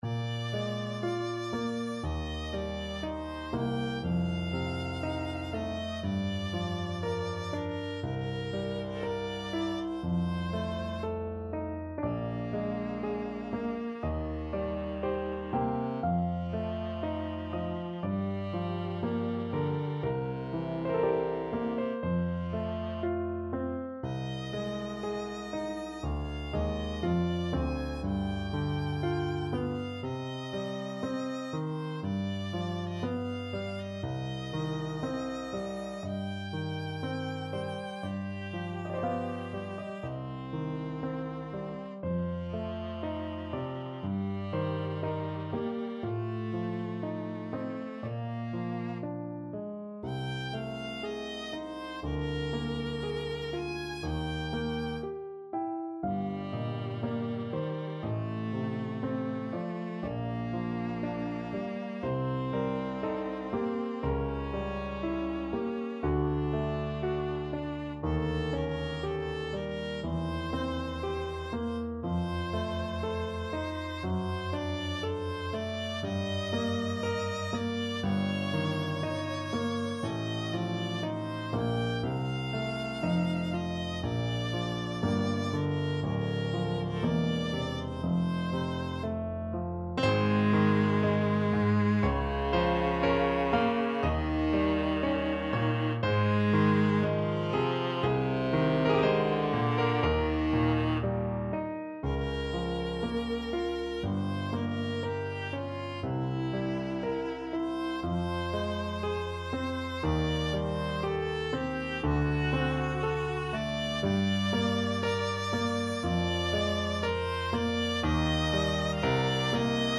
Viola
3/2 (View more 3/2 Music)
Bb major (Sounding Pitch) (View more Bb major Music for Viola )
~ = 60 Largo
Classical (View more Classical Viola Music)